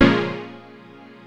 HOUSE 9-L.wav